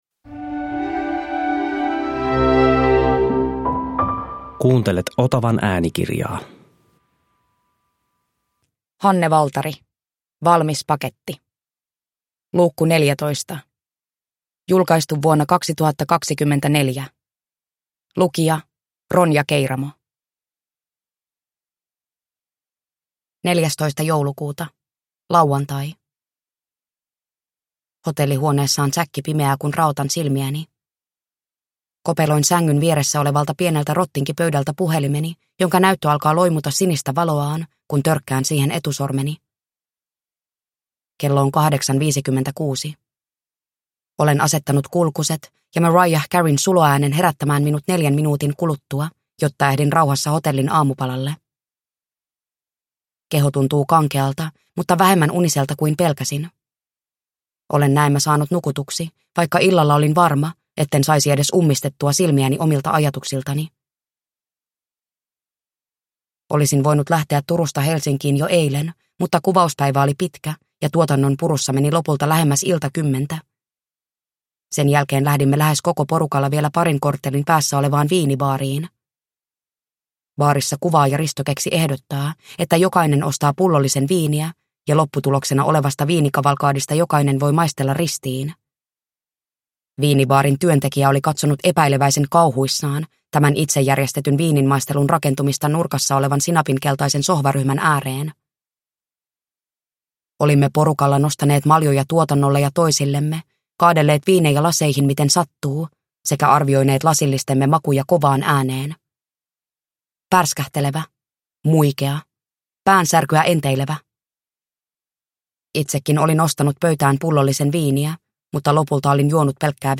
Valmis paketti 14 (ljudbok